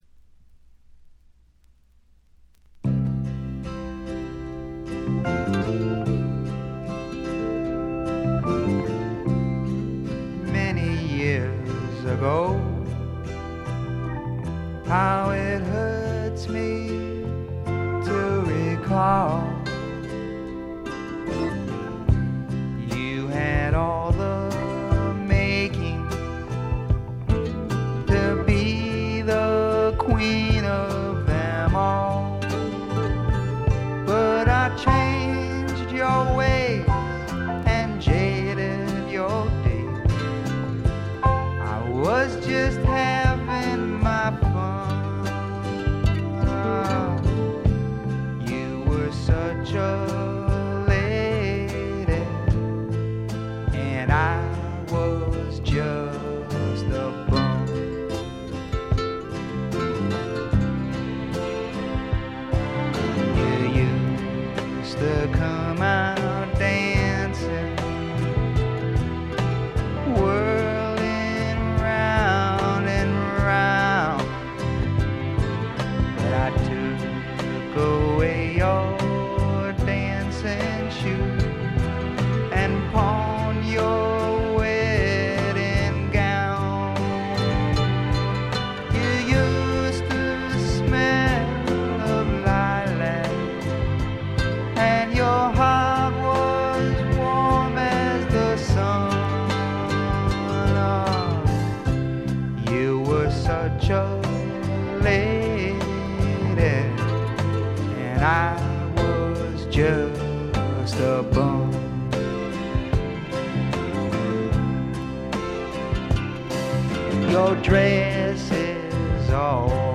ほとんどノイズ感無し。
スワンプ系シンガーソングライター基本。
試聴曲は現品からの取り込み音源です。
Recorded at Muscle Shoals Sound Studios, Muscle Shoals, Ala.